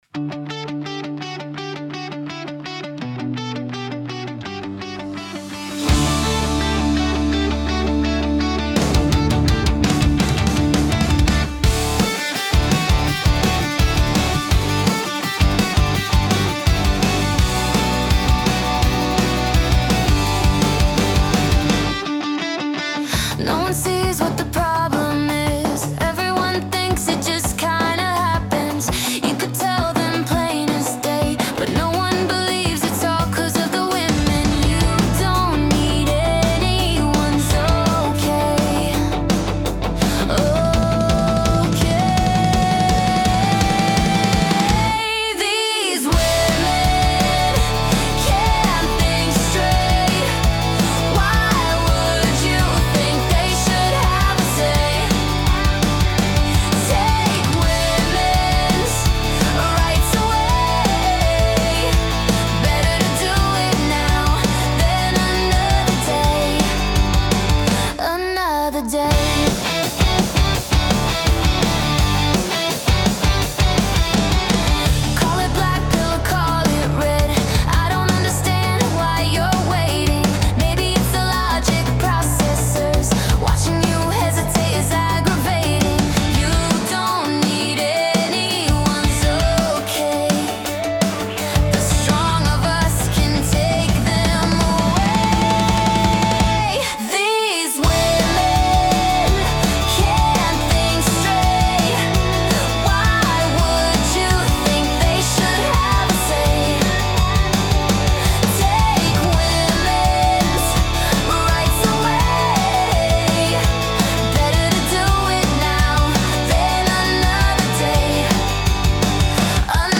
**** This one isn't good enough for the album (it just sounds like one of those lame "Season 2" anime openers that isn't as good as the first one you got used to).